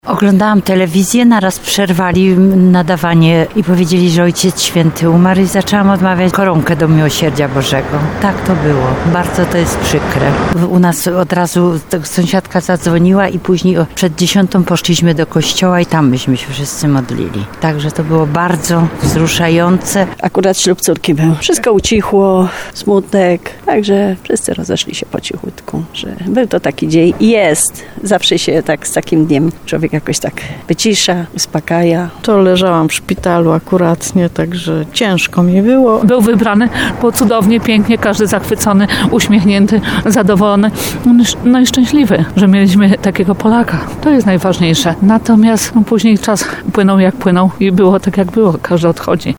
Po latach Tarnowianie wspominają, że ten dzień na zawsze zostanie w ich pamięci.